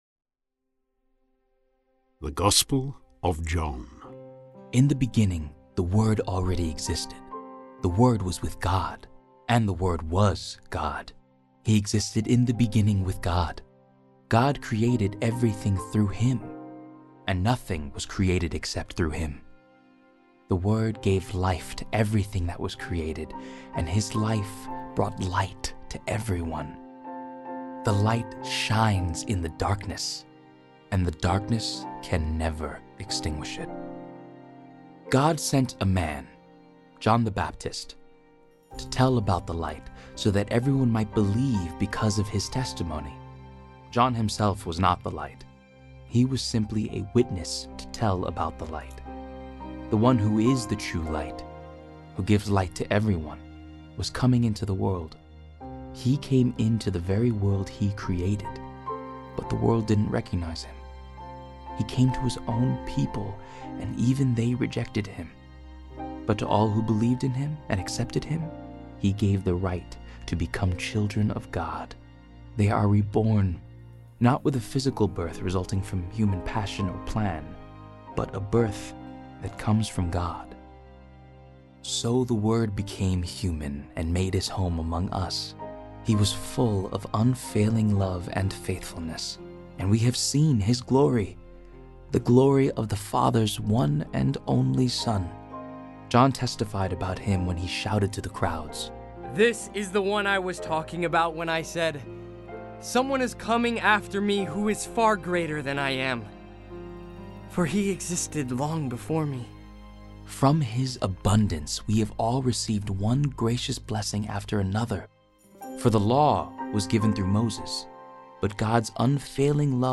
Audio transports you into the stories of the New Testament with cinema-quality sound and original score. Listen as the award-winning cast gives voice to the powerful truth of God’s Word